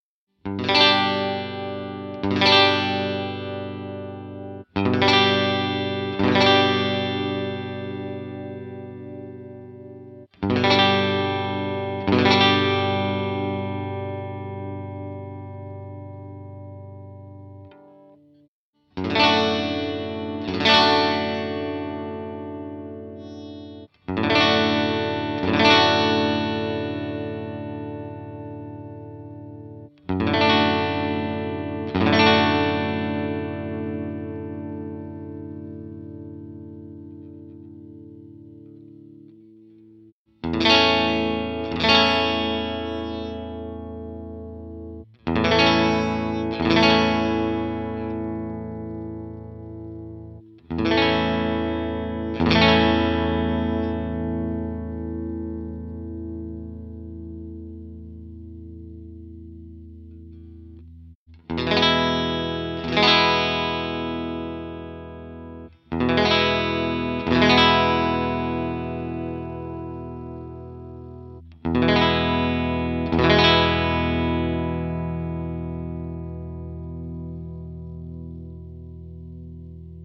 These are pretty boring examples, I did just strumm some chords. Each chord is strummed twice, then the guitar changes. All samples are done with the same cable and for sure its the same amp, because I recorded without amp with a Line6 Pod Xt and the simulation is done on the PC by the Line6 Soundfarm plugin.
So, always first is the Squier CV, then the G&L Legacy and at last the Hohner with the Fender Texas Specials.